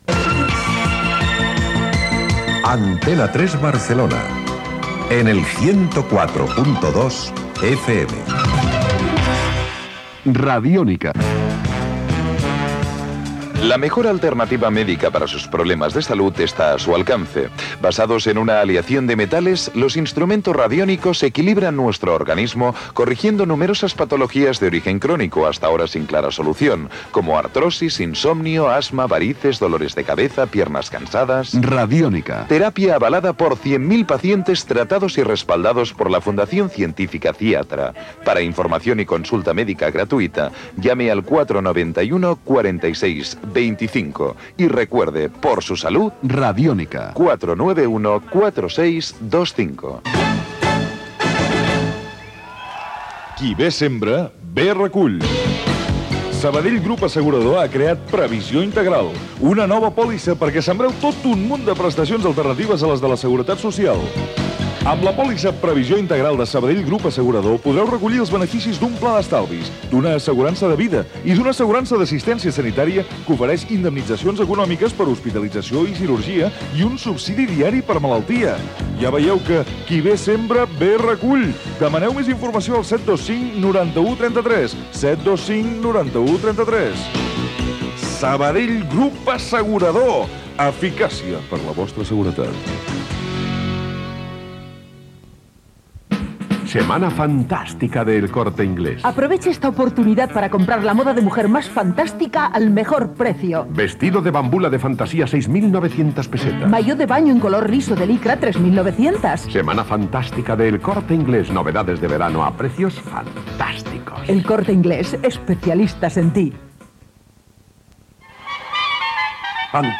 Publicitat, indicatiu, publicitat.
FM